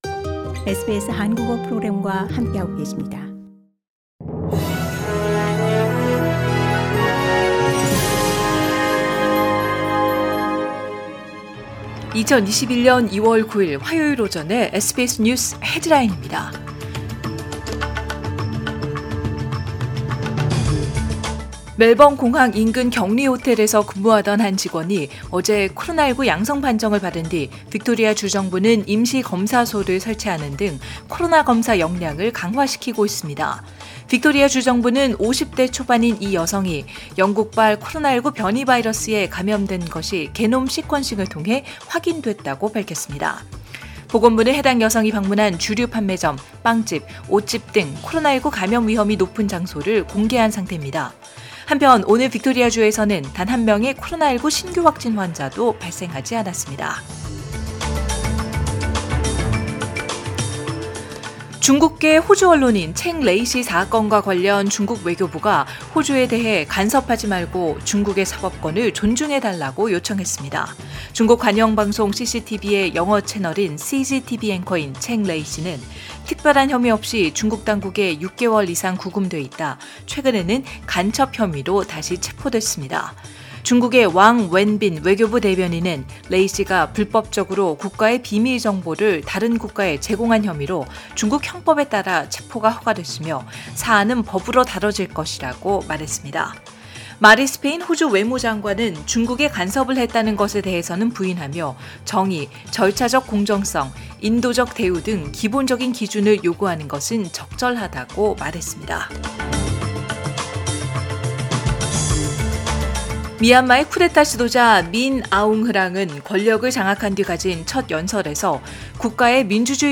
2021년 2월 9일화요일오전의 SBS 뉴스헤드라인입니다.